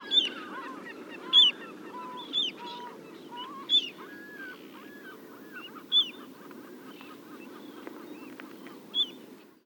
PFR07931-1_1-00_130830_crplov-hiaticula-spec-1-C_dunl-spec-1-C_1019h_seelhaus-see_REF-S
PFR07931, 1-00, 130830, Common ringed plover Charadrius hiaticula, call, Dunlin Calidris alpina, call,
Seelhausener See, Telinga parabolic reflector